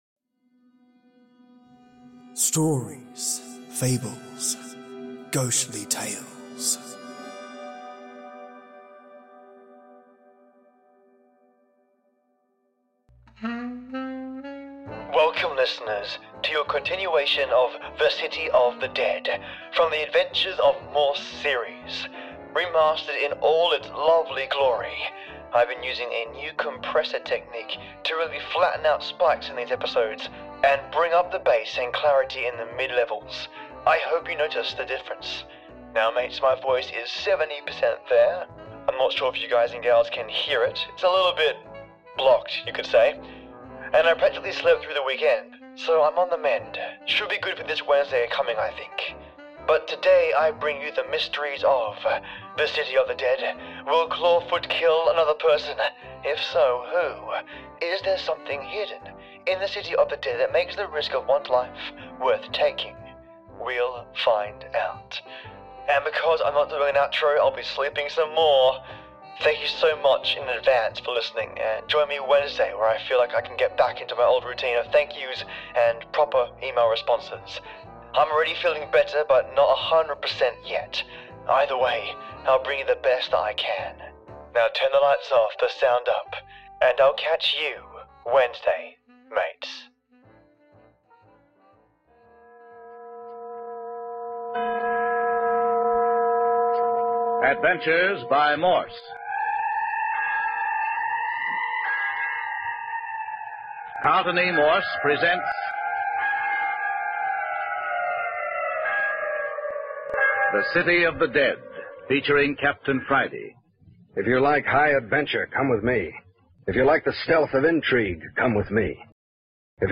Welcome listeners to Part 5 and Part 6 of "The City of the Dead" from The Adventures of Morse Old Time Radio series.
All Radio episodes remastered are in the Public Domain unless notified otherwise.